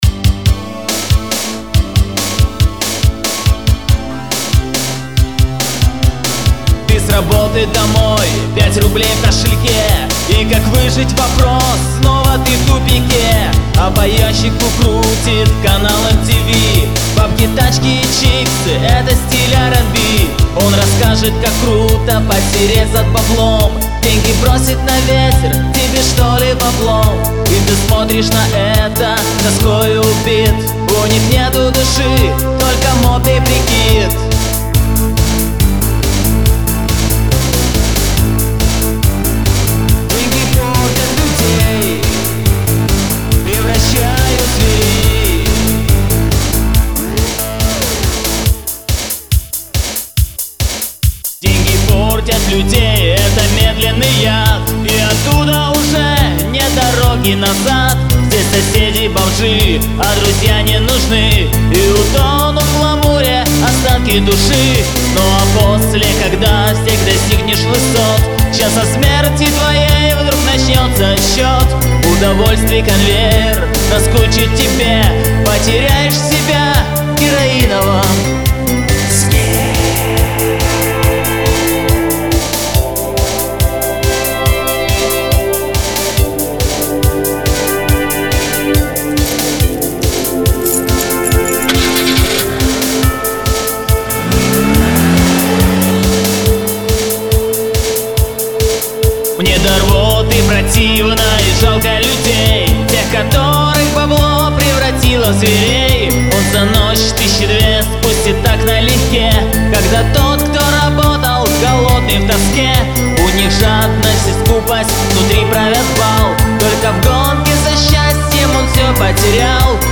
Synthpop version